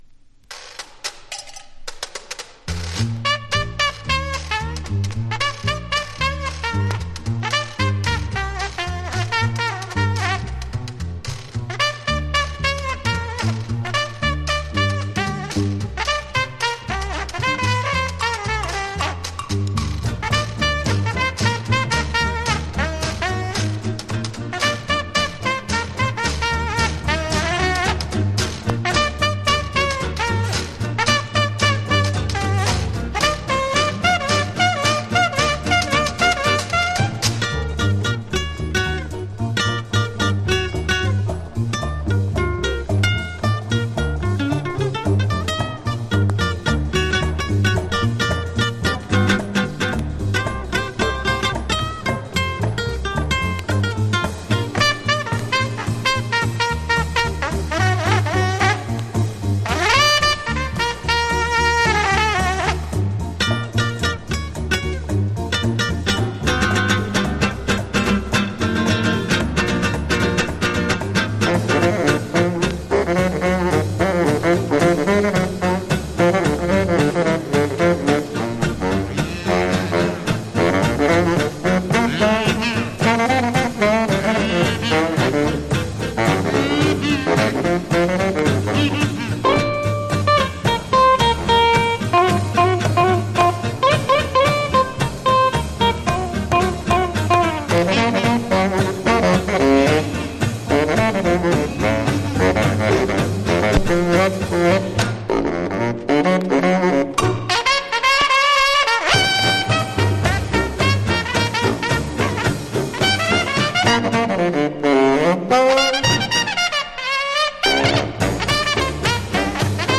コルネット
（小傷によりチリ、プチ音ある曲あり）※曲…